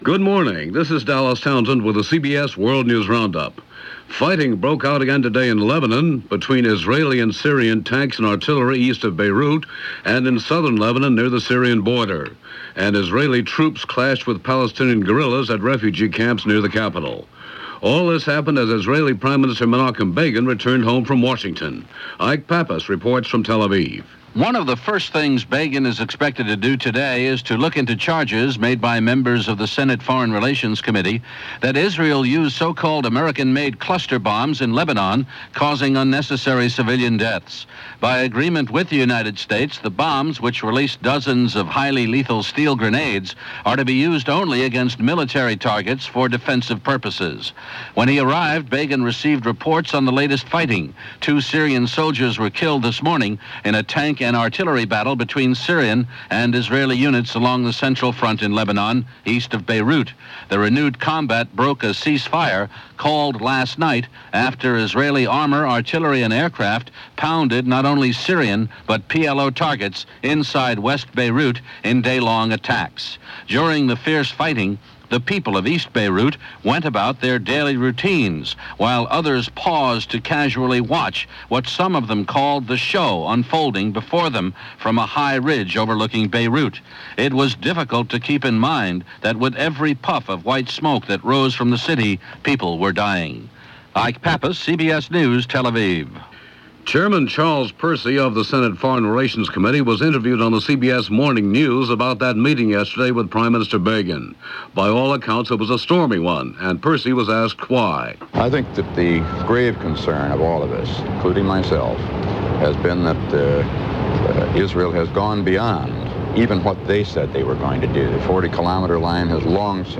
June 23, 1982 - Lebanon: A Clash of Tanks - ERA: A Clash of ideologies - news of the day. The situation in Lebanon, Capitol Hill, and much more.